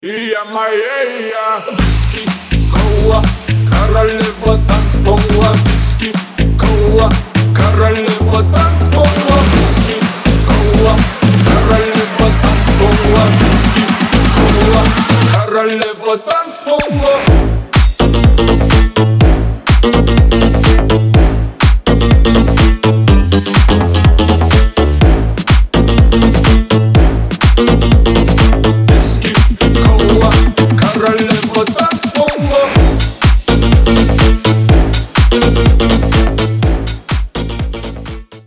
Club House
струнные
electro house
Стиль: club house, electro house